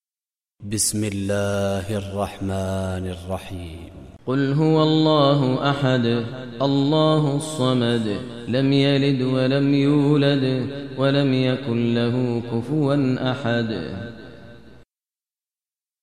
Surah Ikhlas Recitation by Sheikh Maher Mueaqly
Surah Ikhlas, listen online mp3 tilawat / recitation in Arabic in the voice of Imam e Kaaba Sheikh Maher Mueaqly.